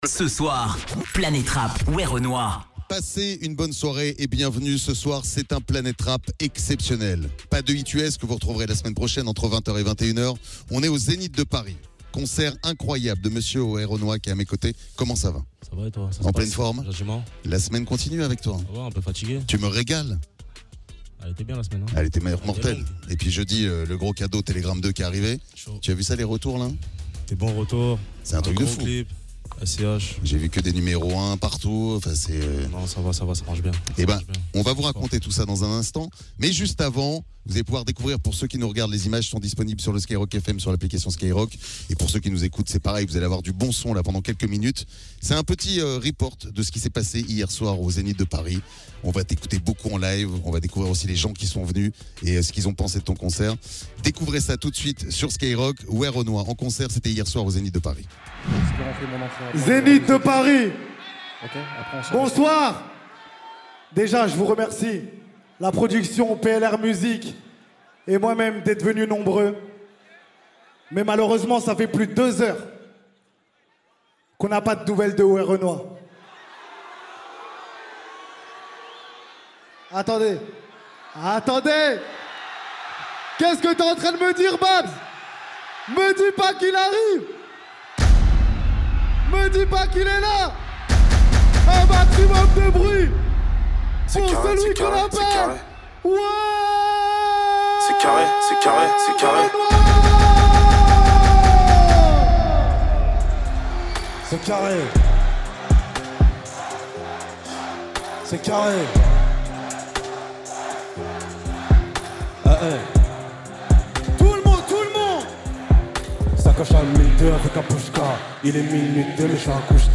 Au menu chaque soir entre 20h et 21h : interviews, exclus, et freestyles légendaires ! Planète Rap, c'est l'émission rap de référence !